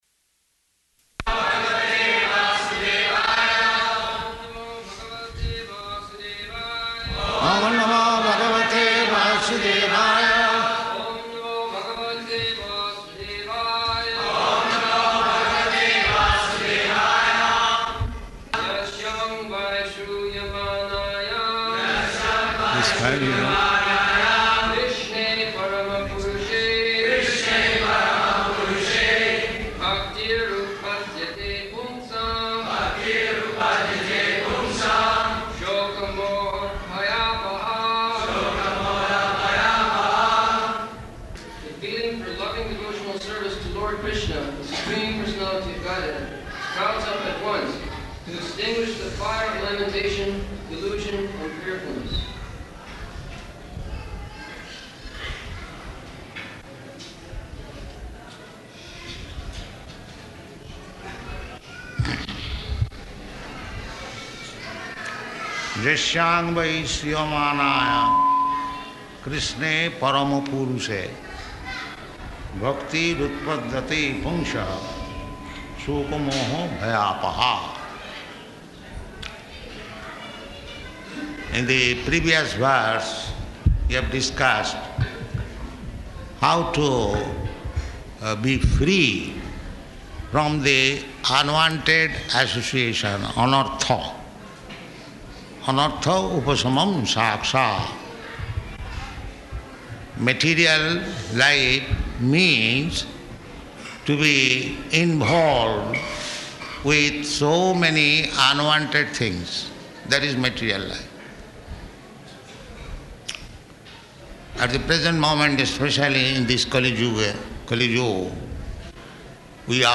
April 24th 1975 Location: Vṛndāvana Audio file
[Prabhupāda and devotees repeat] [leads chanting of verse, etc.]